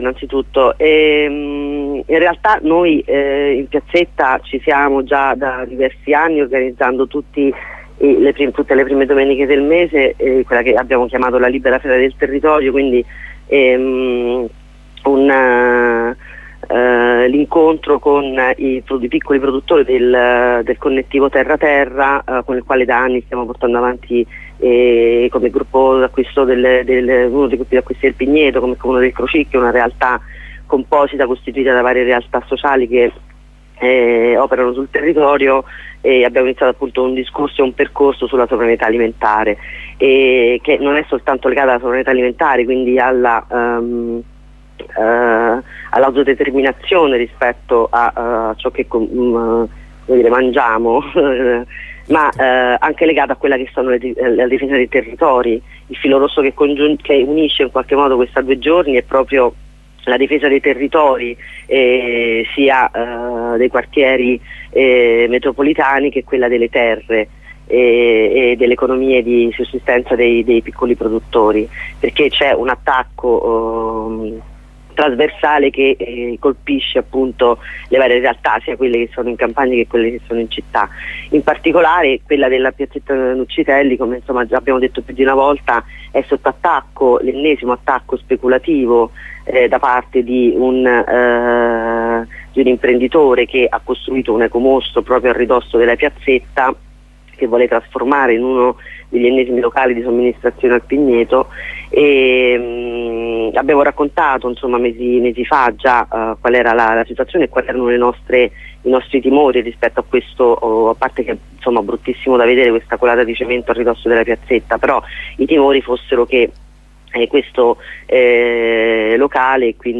Ne parliamo con una delle organizzatrici dell'iniziativa